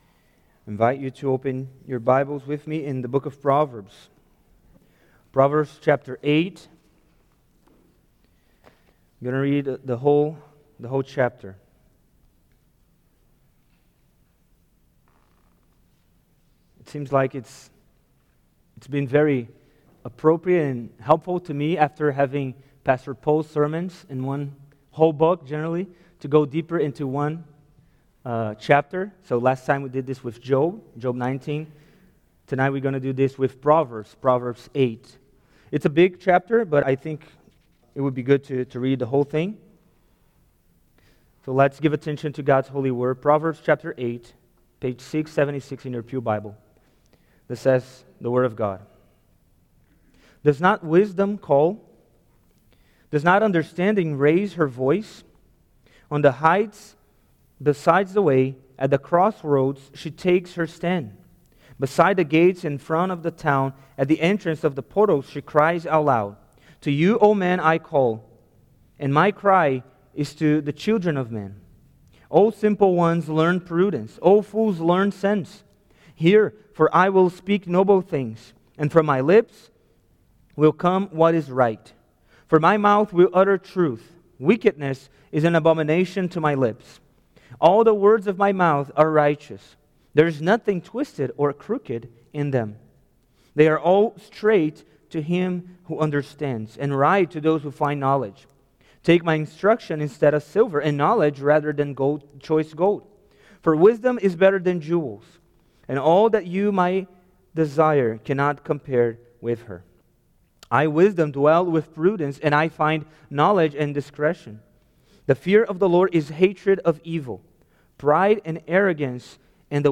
Wisdom Series Various Sermons Book Proverbs Watch Listen Save In Proverbs 8:1-36, wisdom calls the listener to seek wisdom and to gain blessings and even the gift of life.